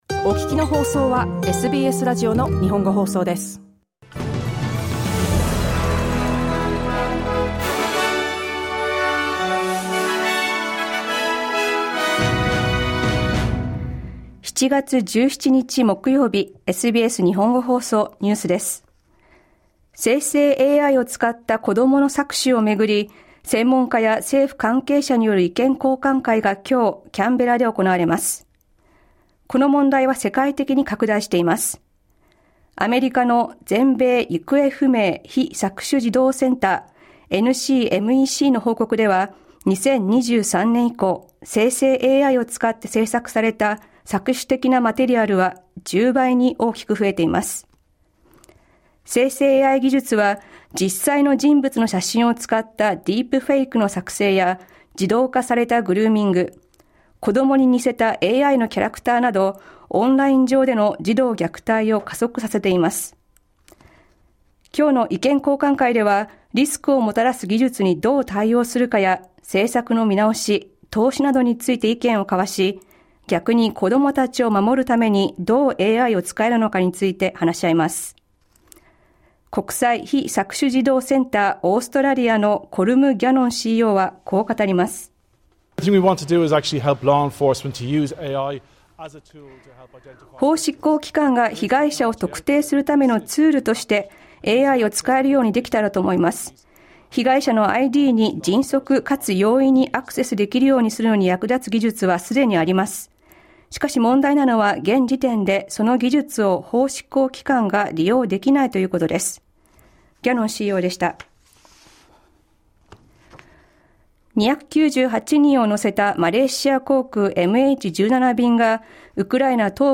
SBS日本語放送ニュース7月17日木曜日